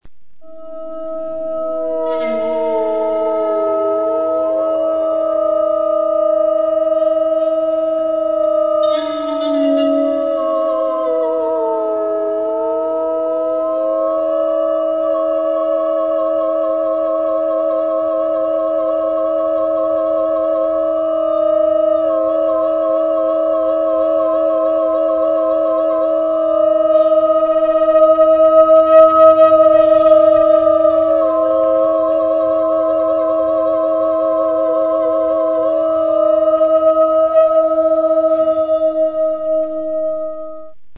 Fence music, radiophonic